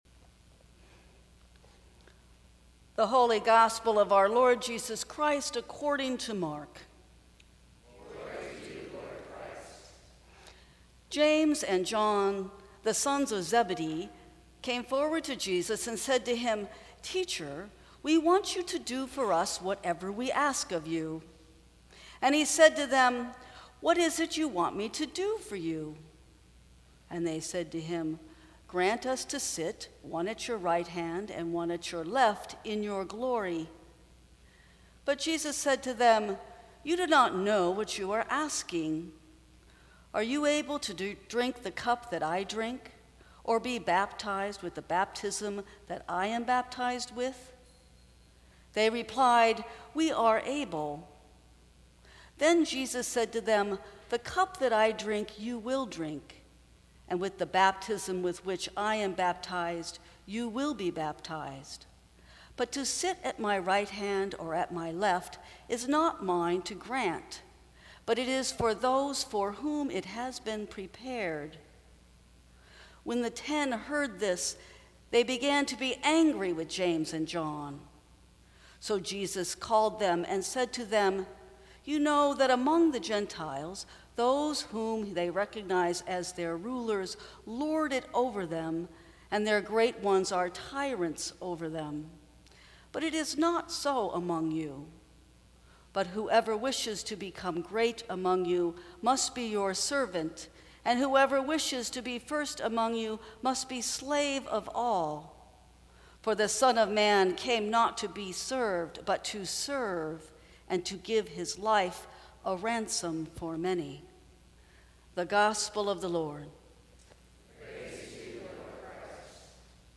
Sermons from St. Cross Episcopal Church What Is it You Want Me to Do for You?